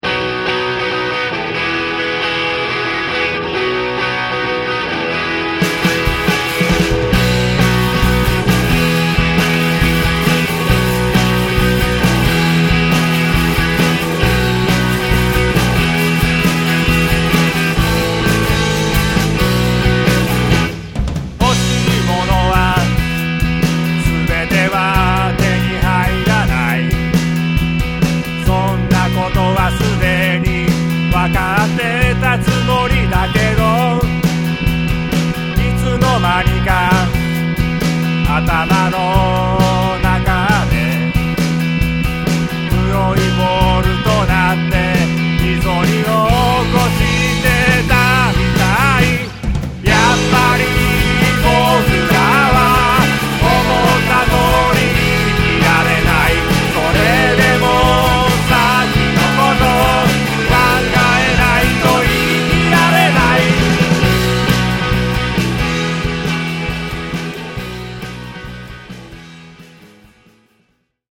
唄がうまくないけど、いいバンドだなぁ。